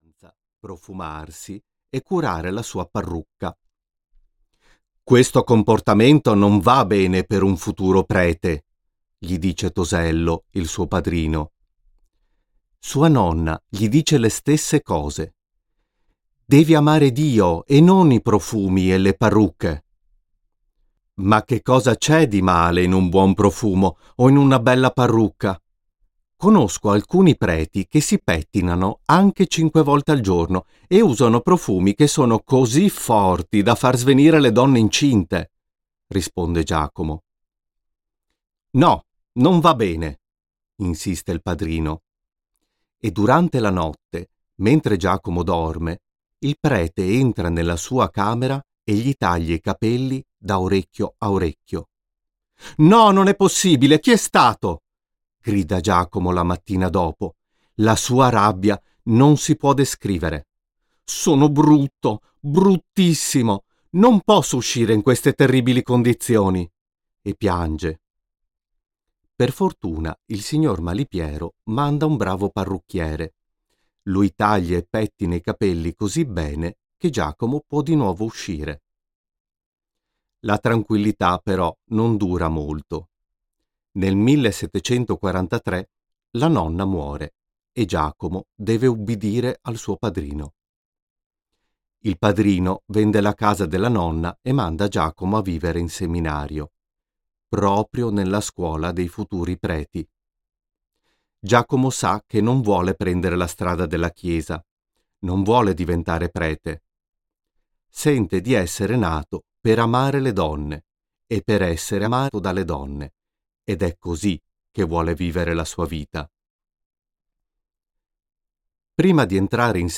Storia di Casanova (IT) audiokniha